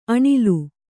♪ aṇilu